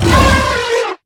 combat / enemy / baurg